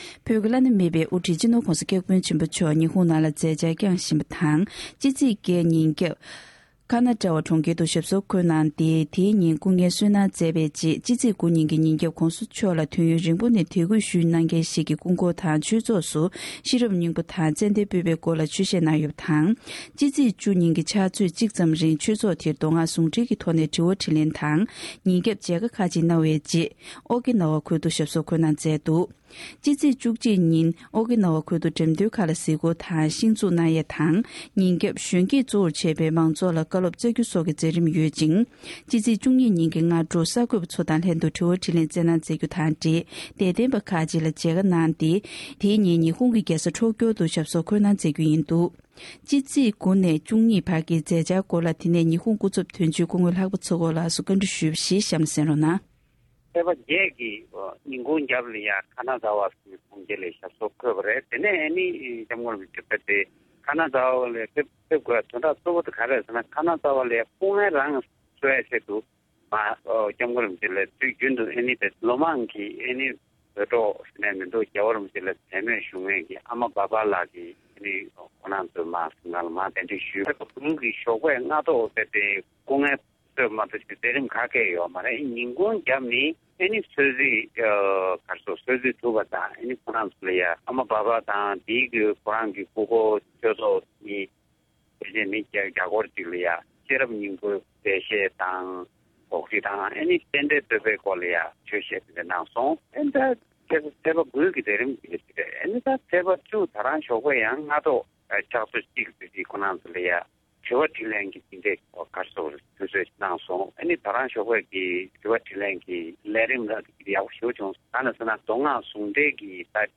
སྒྲ་ལྡན་གསར་འགྱུར།
༄༅༎བོད་ཀྱི་བླ་ན་མེད་པའི་དབུ་ཁྲིད་སྤྱི་ནོར་༸གོང་ས་༸སྐྱབས་མགོན་ཆེན་པོ་མཆོག་ཉི་ཧོང་དུ་མཛད་འཆར་སྐྱོང་གནང་བཞིན་ཡོད་པ་དང་། ཕྱི་ཚེས་༩ནས་༡༢བར་གྱི་མཛད་འཆར་སྐོར་ཉི་ཧོང་སྐུ་ཚབ་དོན་གཅོད་སྐུ་ངོ་ལྷག་པ་འཚོ་སྒོ་ལགས་ཀྱིས། འདི་ག་རླུང་འཕྲིན་ཁང་དུ་ངོ་སྤྲོད་གནང་བར་གསན་རོགས་ཞུ༎